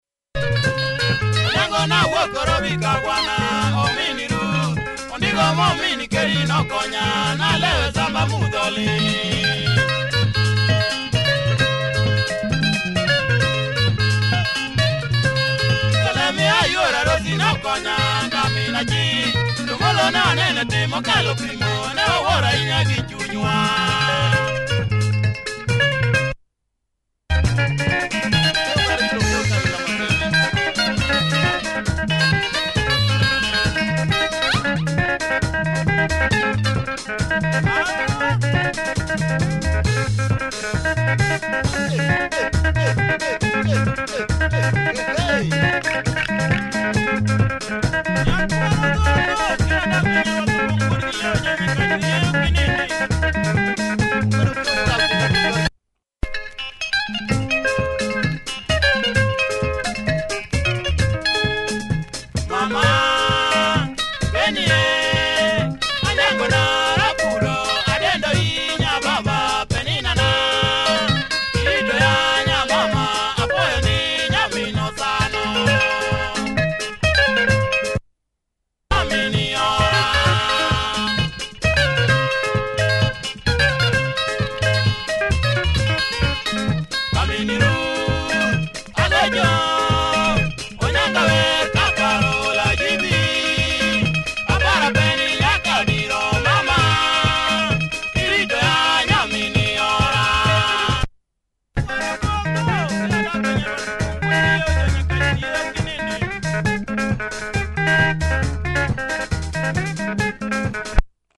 Luo benga